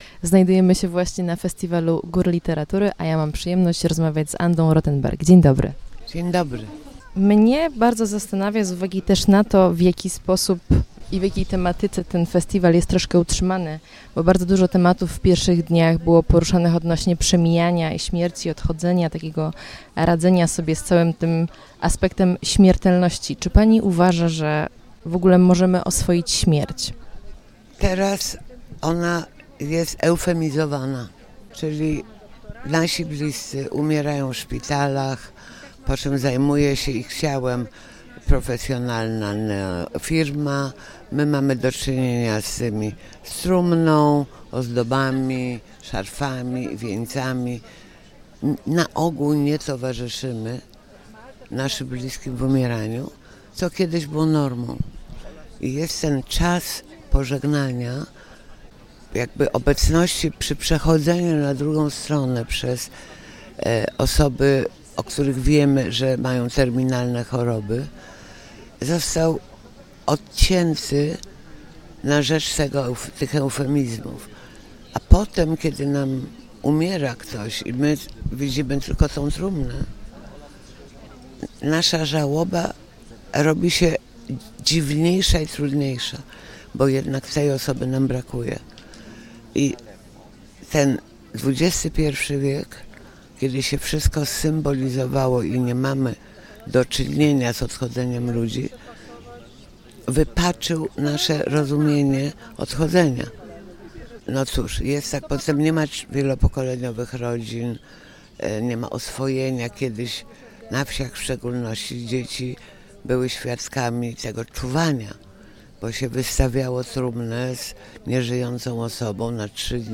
Podczas tegorocznej edycji Festiwalu Góry Literatury miałam przyjemność rozmawiać z historyczką sztuki.